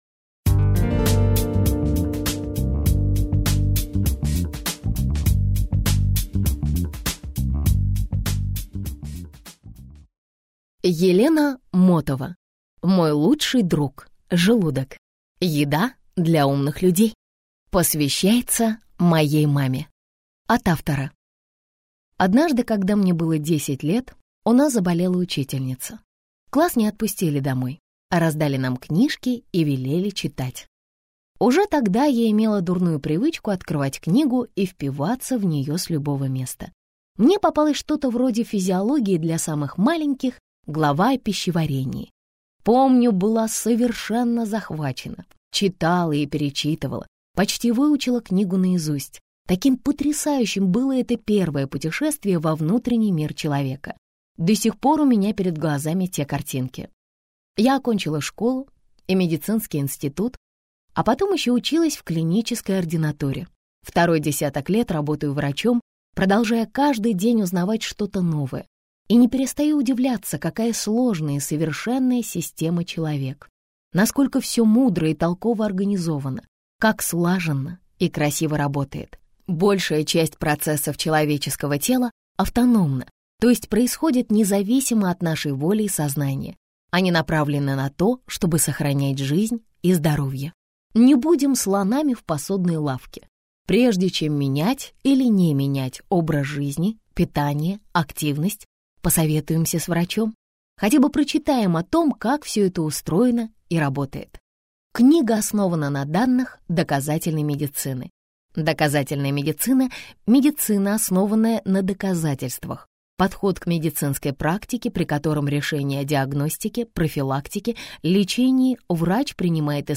Аудиокнига Мой лучший друг – желудок. Еда для умных людей - купить, скачать и слушать онлайн | КнигоПоиск